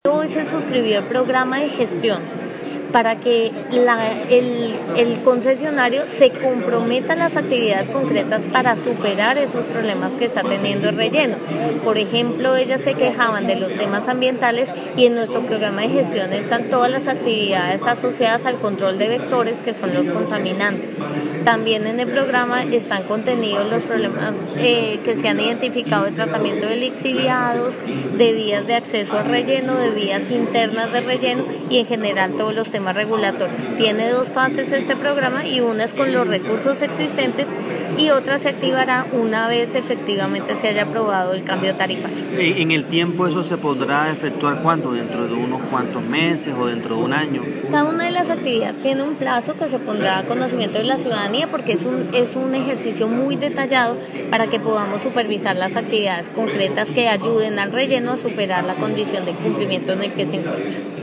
Archivo MP3Audio declaraciones Rutty Paola Ortiz